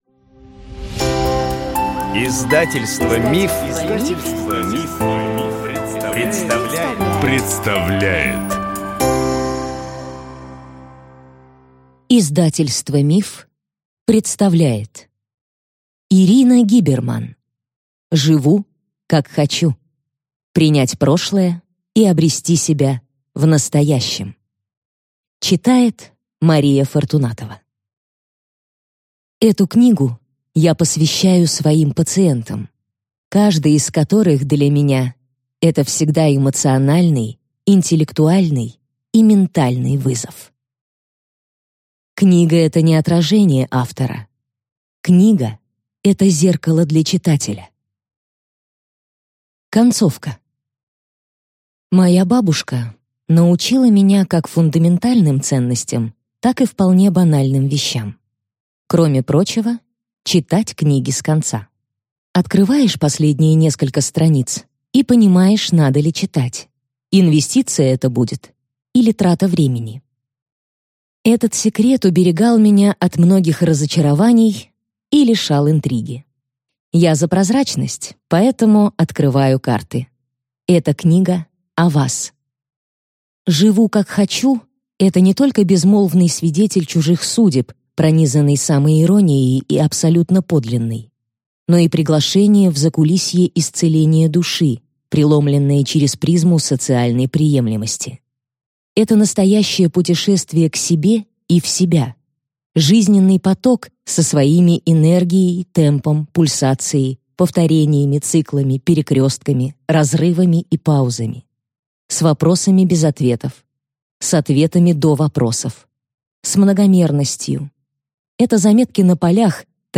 Аудиокнига Живу как хочу. Принять прошлое и обрести себя в настоящем | Библиотека аудиокниг
Прослушать и бесплатно скачать фрагмент аудиокниги